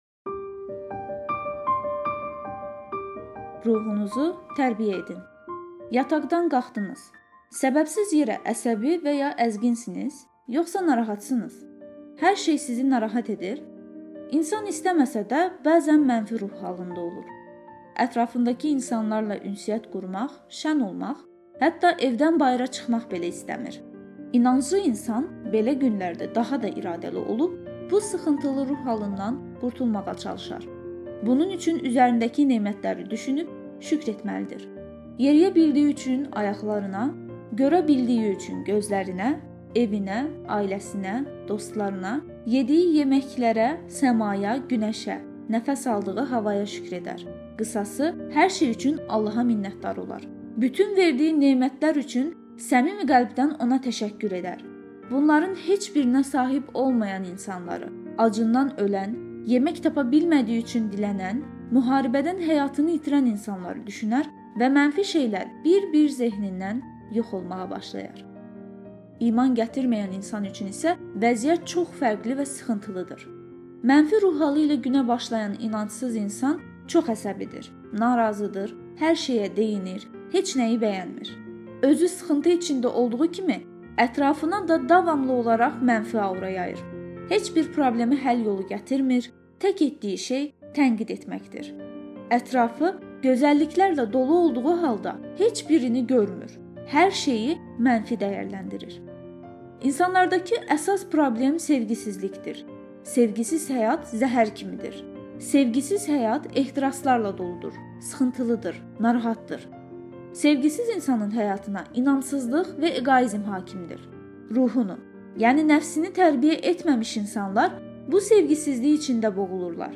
Adnan Oktarın Kaçkar telekanalında verdiyi canlı müsahibəsi (15 dekabr 2010)Adnan Oktar: Onlara 'z Rəbbindən yeni bir xatırlatma gəldikdə deyir.